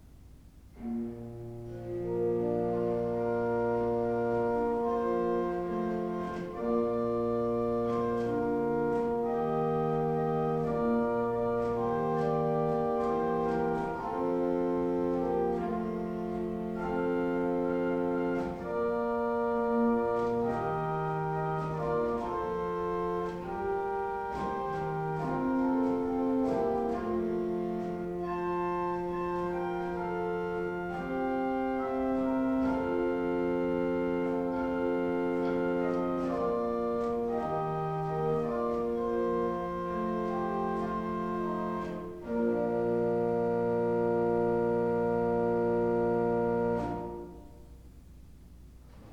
1787 Tannenberg Organ
To listen to an improvisation on the Viol de Gambe 8', click
Improvisation_Viol_de_Gambe_8F.wav